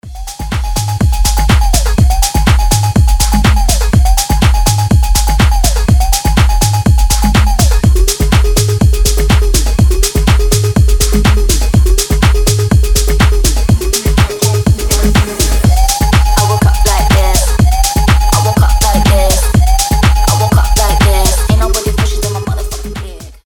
клубные
electronic , заводные , edm , бодрые , tech house